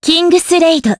voices / heroes / jp
Xerah-Vox_Kingsraid_jp.wav